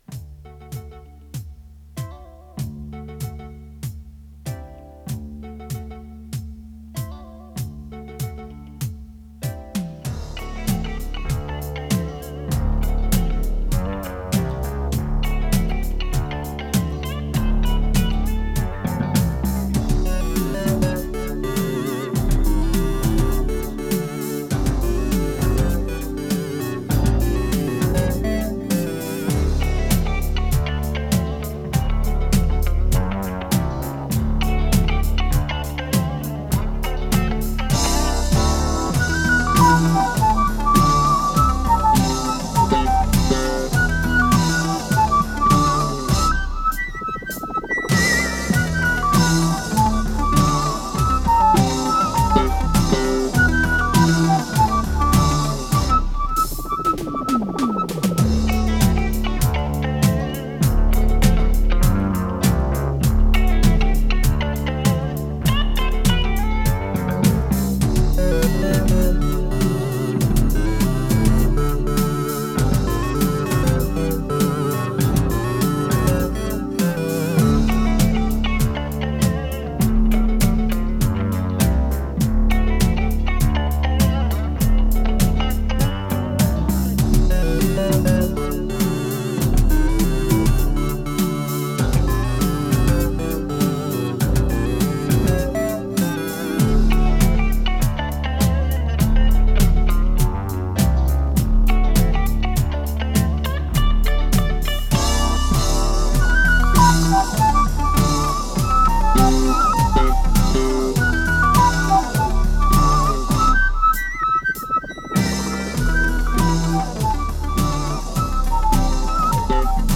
с профессиональной магнитной ленты
Скорость ленты38 см/с